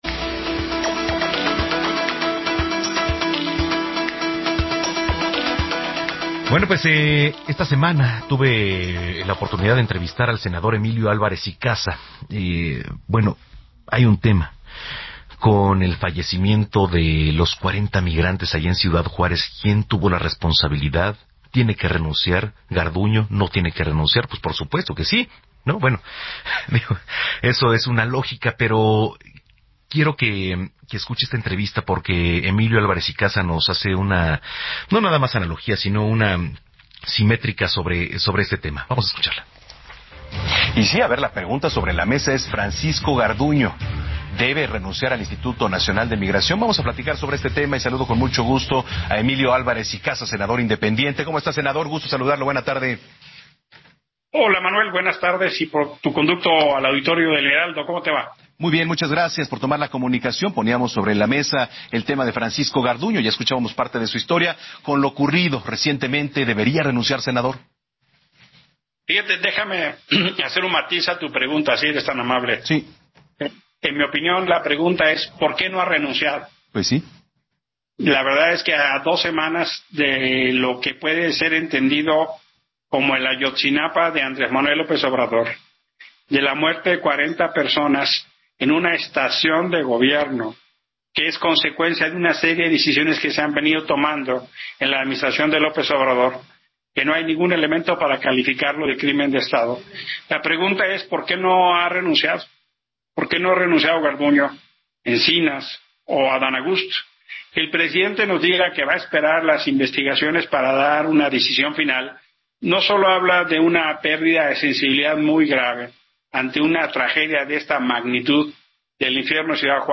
En entrevista, el Senador Emilio Álvarez Icaza, habló del caso del incendio en la estación migratoria de Ciudad Juárez, Chihuahua.